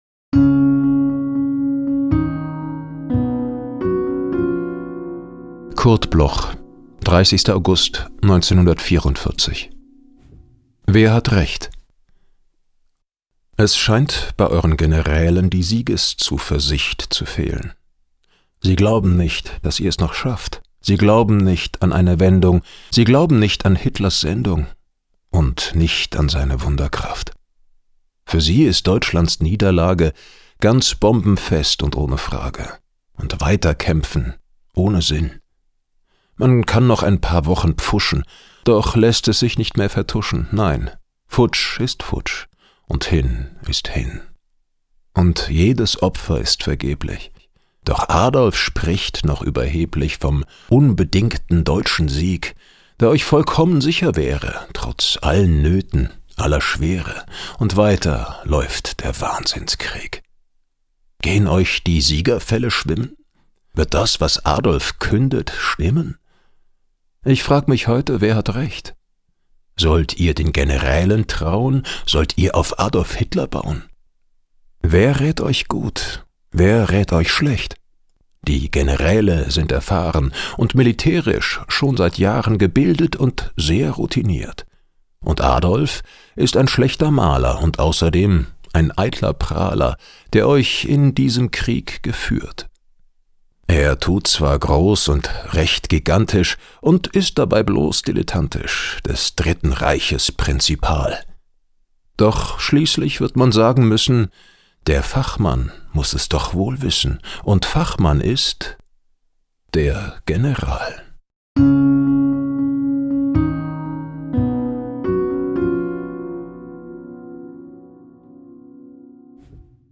Goetz-Otto-Wer-hat-Recht-mit-Musik_raw.mp3